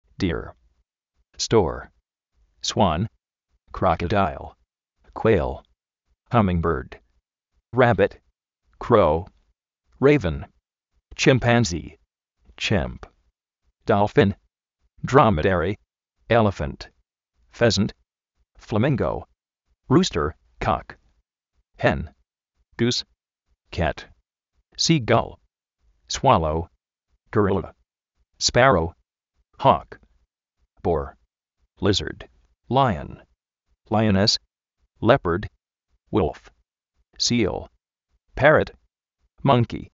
Vocabulario en ingles, diccionarios de ingles sonoros, con sonido, parlantes, curso de ingles gratis
sí:bra
píg